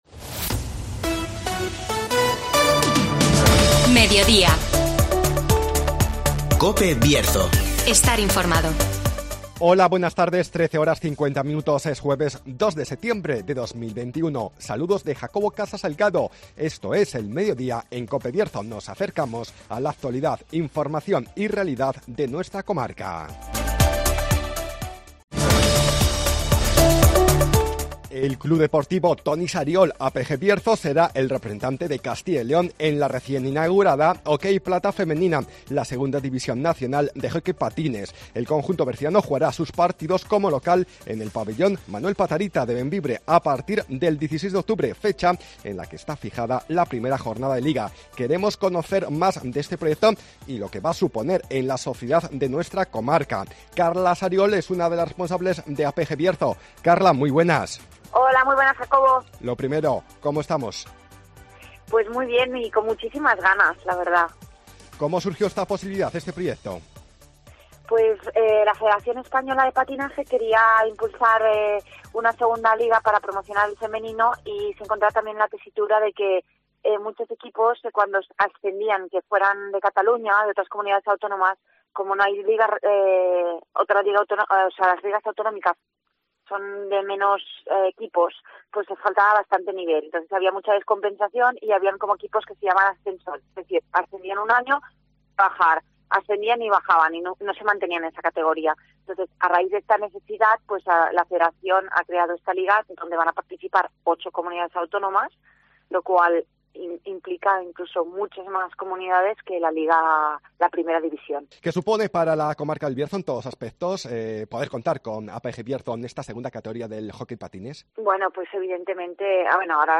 El club de hockey patines APG Bierzo representará a Castilla y León en la OK Liga Plata Femenina (Entrevista